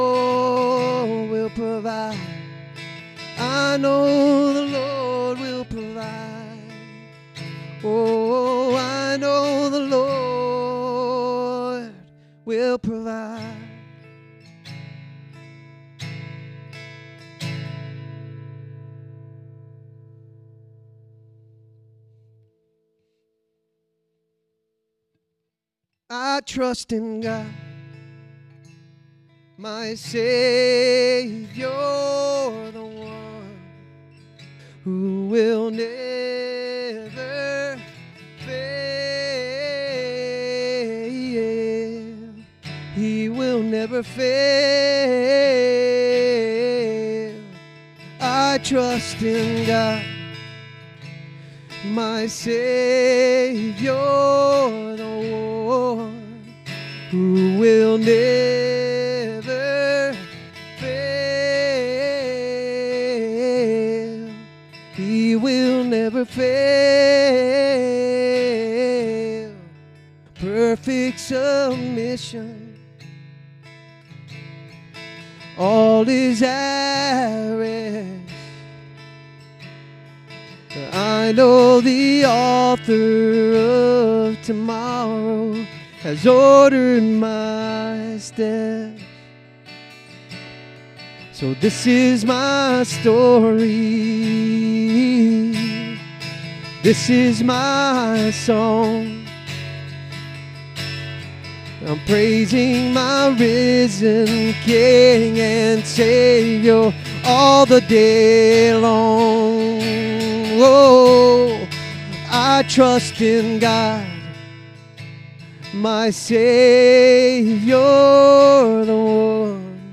Download Download Reference Acts 4:23-37, Ephesians 3:19 Sermon Notes Click here for notes 250216.pdf SERMON DESCRIPTION Every moment of God has leaders.